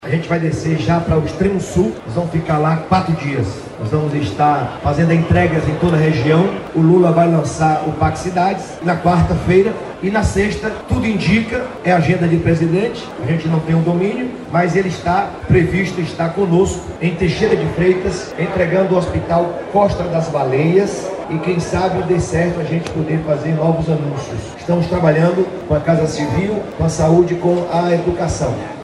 🎙 Governador Jerônimo Rodrigues